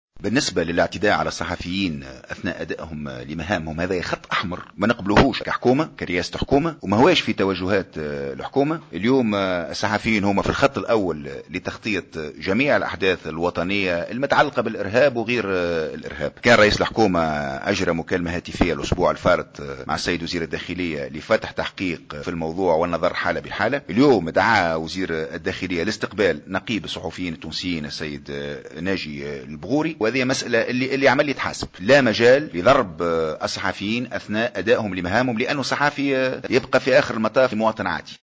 تصريح اعلامي